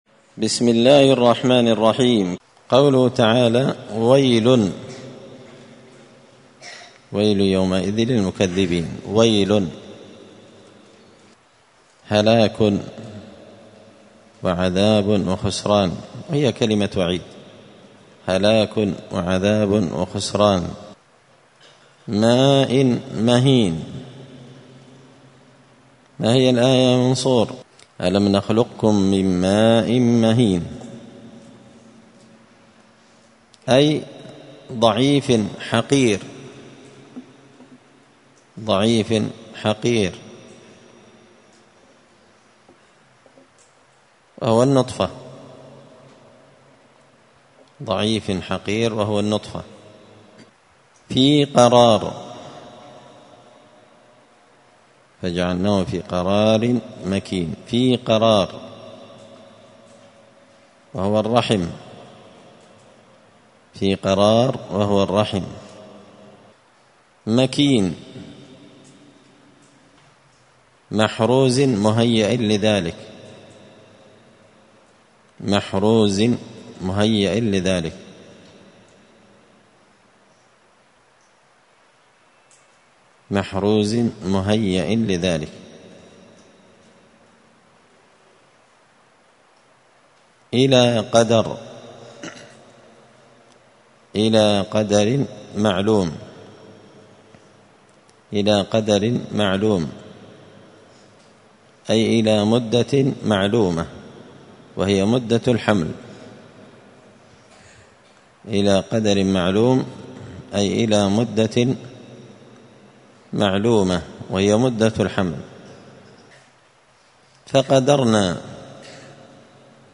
مسجد الفرقان قشن_المهرة_اليمن 📌الدروس اليومية